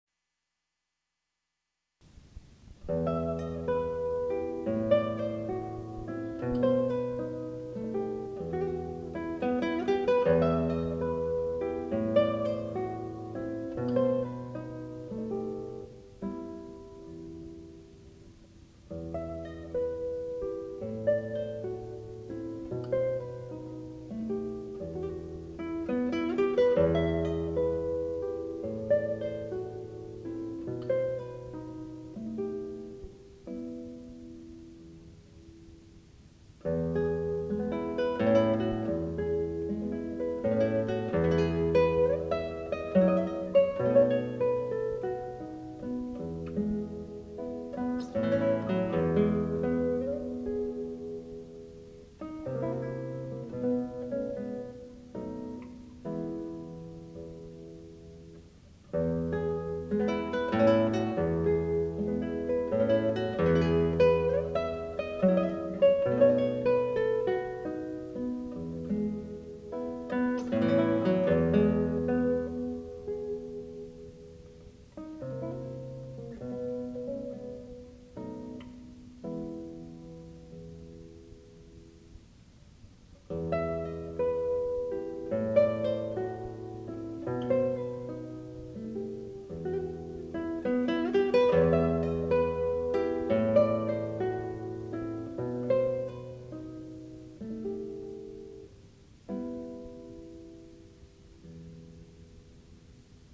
This guitarist performs the standards for your wedding or social event on the classical acoustic guitar, and can play subtle "jazz" electric guitar for your cocktail hour or other intimate gathering.
guitar